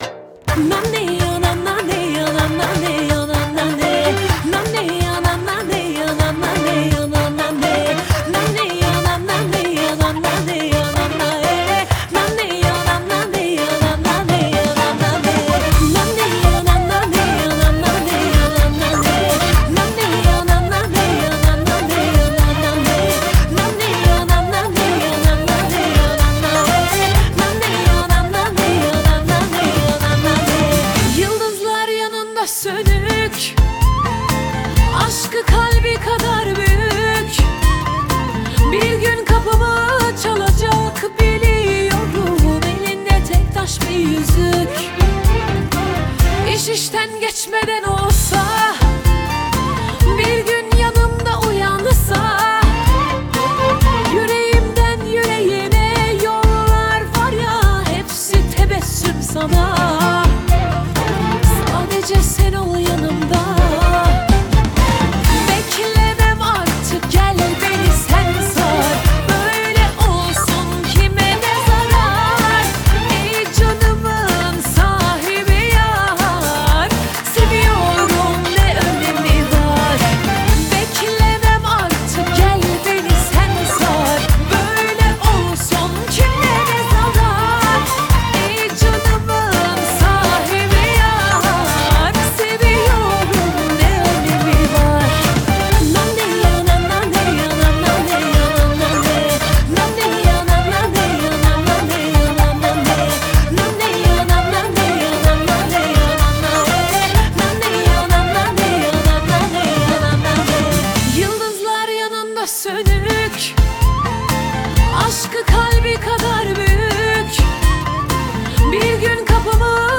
آهنگ ترکیه ای آهنگ شاد ترکیه ای آهنگ هیت ترکیه ای